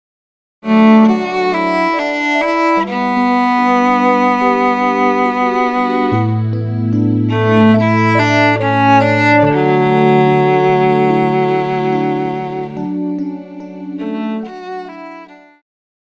流行,宗教
乐团
经典曲目,流行音乐,教会音乐
演奏曲
抒情歌曲
仅伴奏
没有主奏
有节拍器